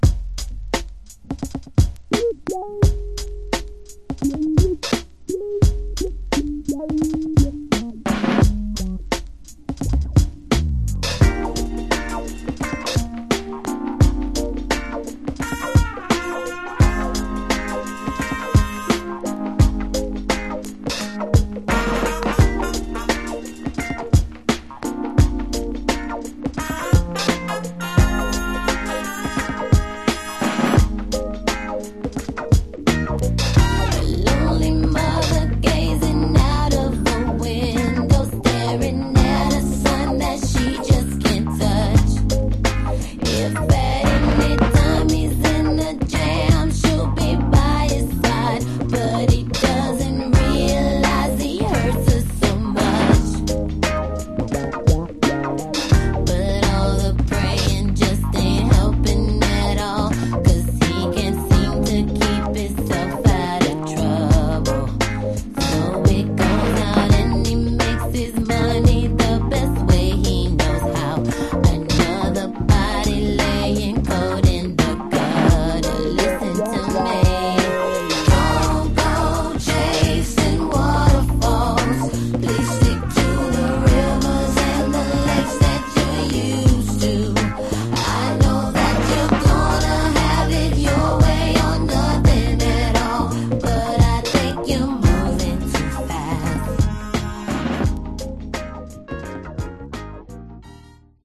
Genre: Funk/Hip-Hop/Go-Go